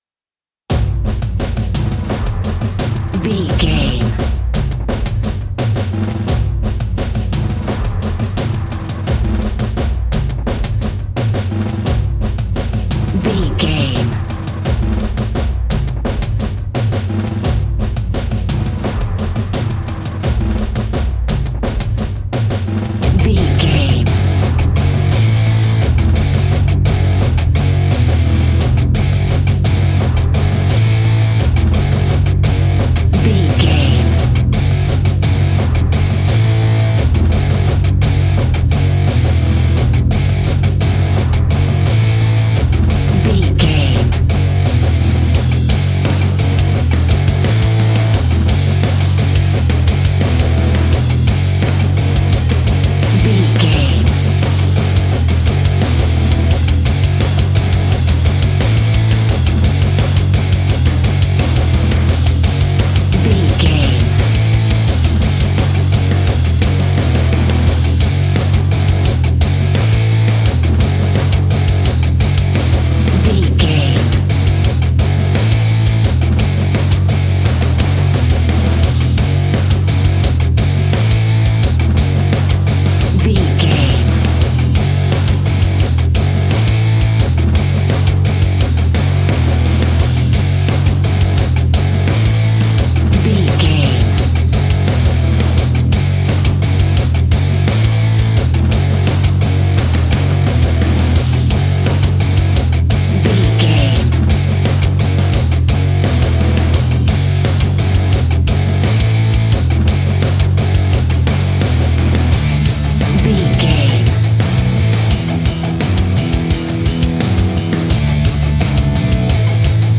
Epic / Action
Fast paced
Aeolian/Minor
F#
hard rock
heavy metal
horror rock
instrumentals
Heavy Metal Guitars
Metal Drums
Heavy Bass Guitars